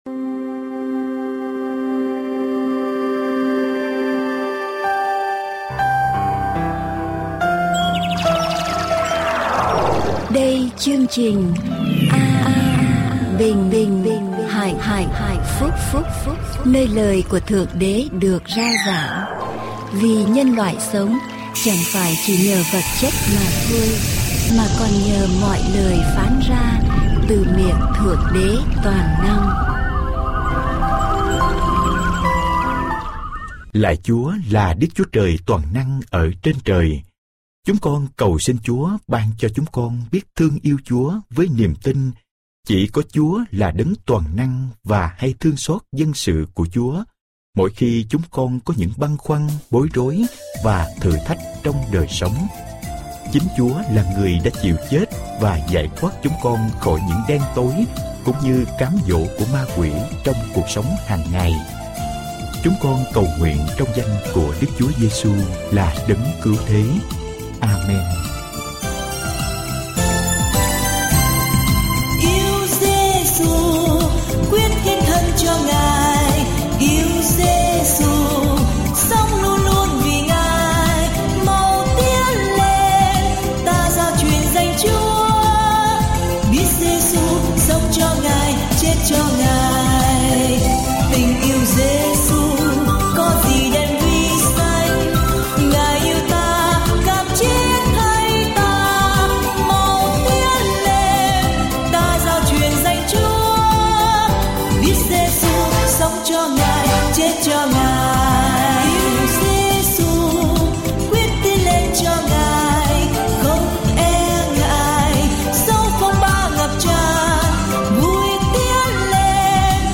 Adventist Vietnamese Sermon